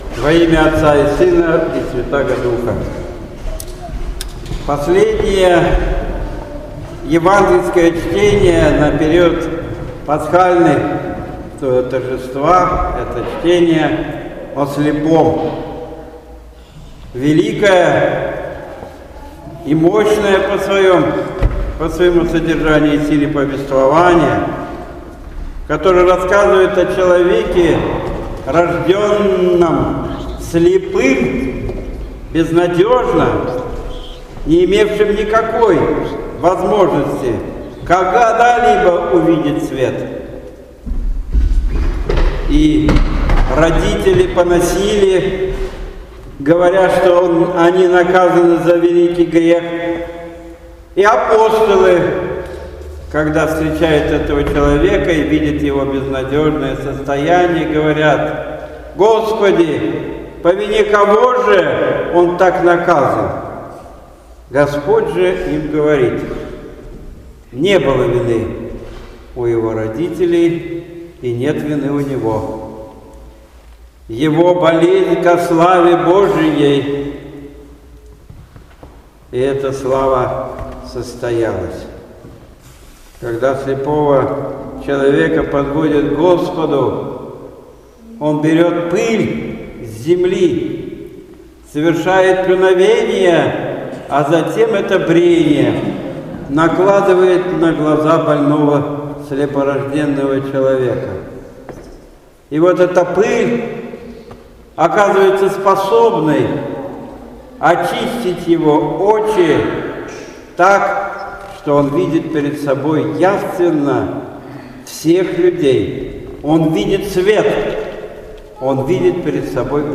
Божественная Литургия в неделю о слепом 25 мая 2014 года